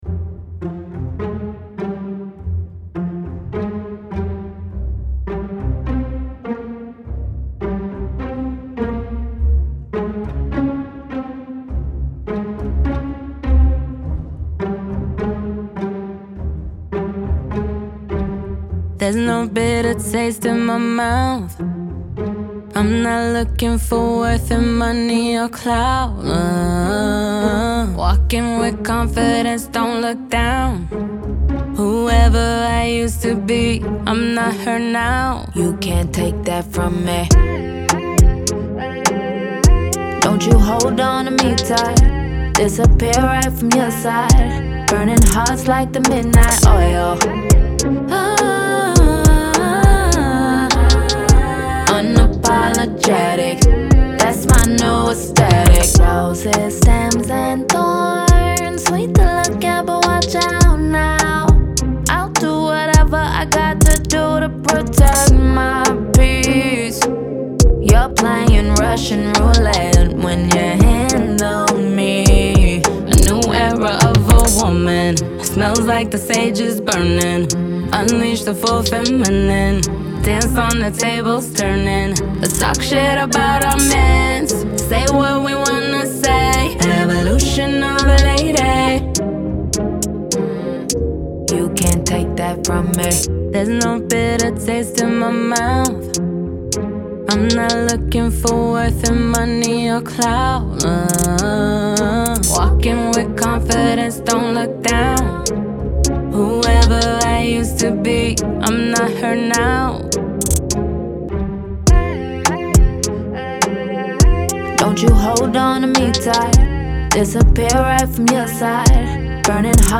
R&B, Pop
E min